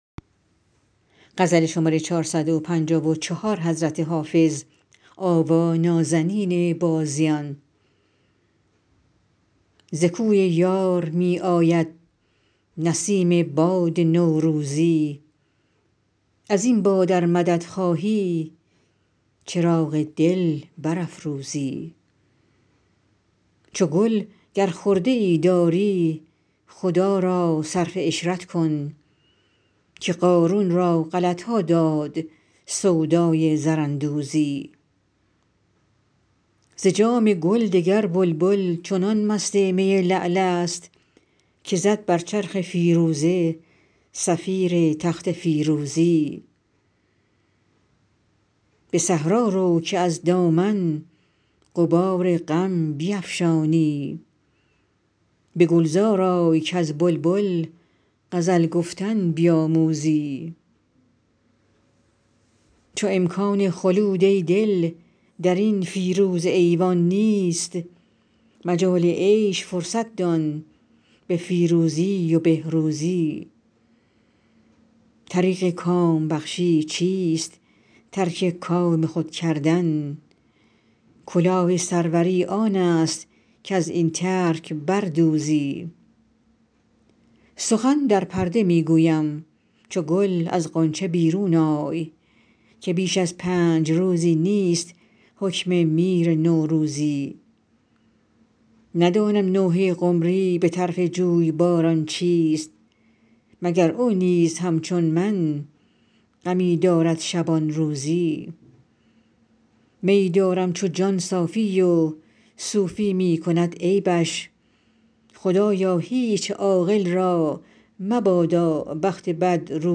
حافظ غزلیات غزل شمارهٔ ۴۵۴ به خوانش